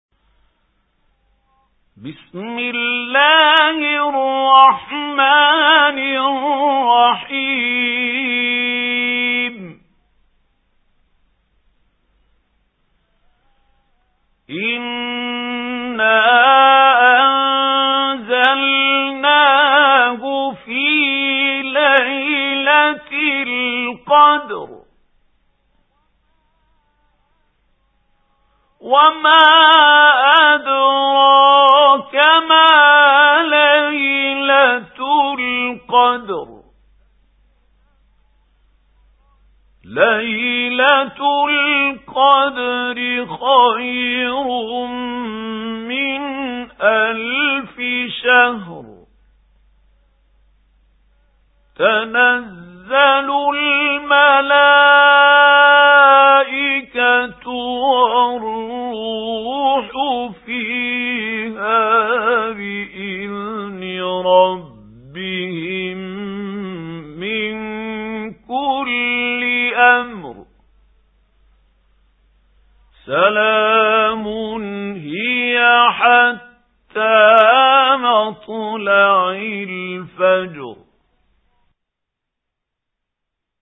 سُورَةُ القَدۡرِ بصوت الشيخ محمود خليل الحصري